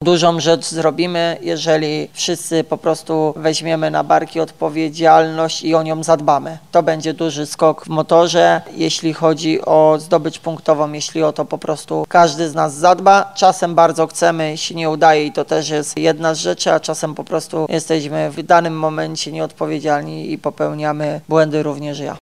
Na przedmeczowej konferencji prasowej